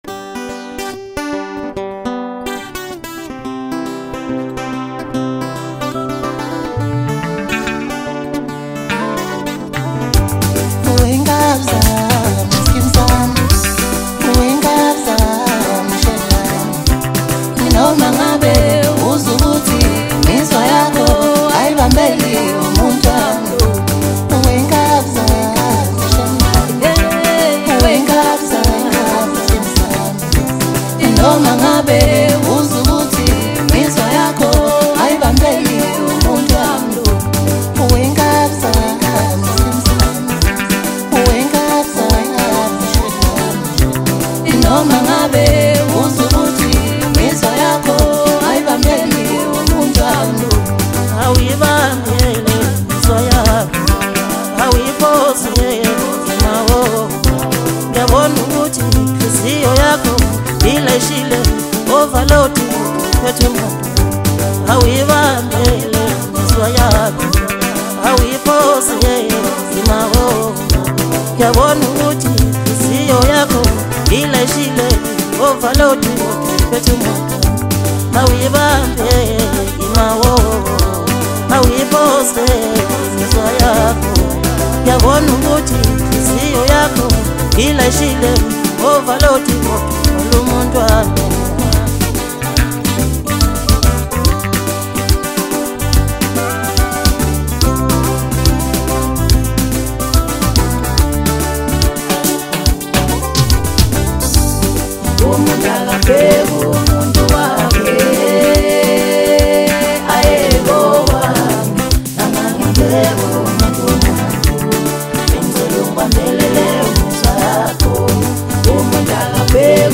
Maskandi, DJ Mix, Hip Hop
South African Maskandi singer-songwriter